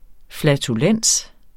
Udtale [ flatuˈlεnˀs ]